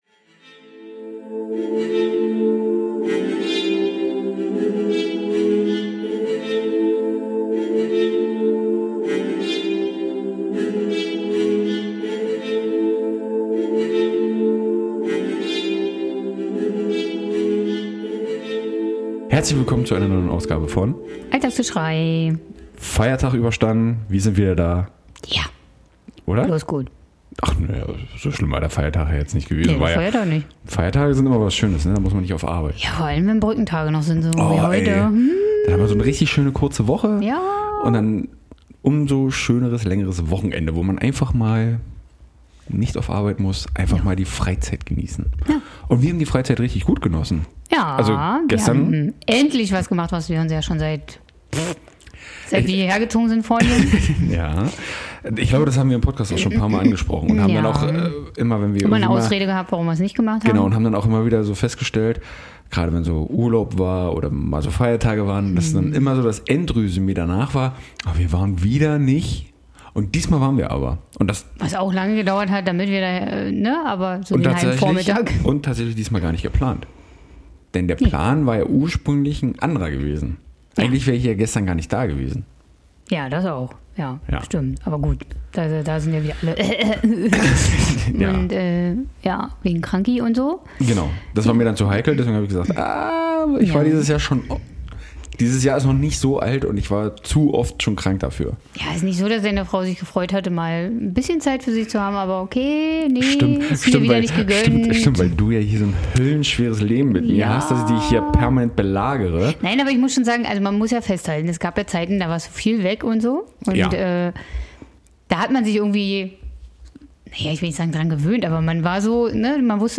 ;) Unsere Songs sind natürlich wie immer auch dabei.